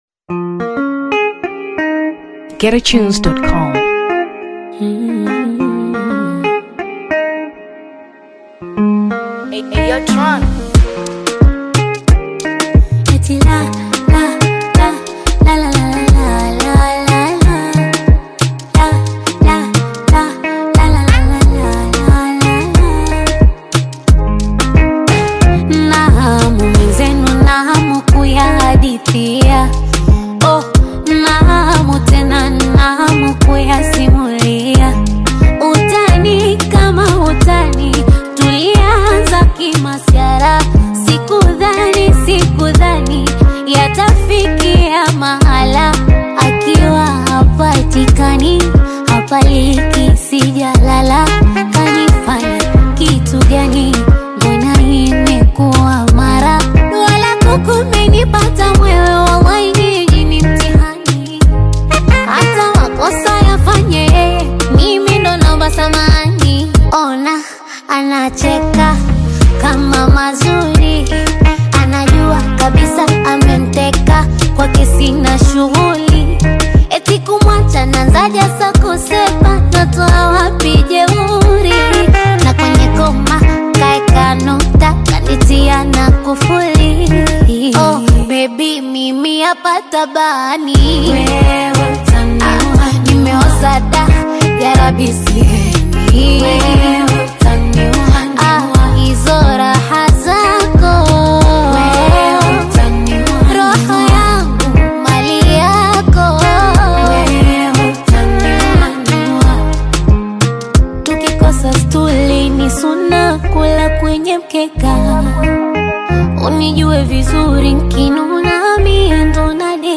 Afrobeats 2023 Tanzania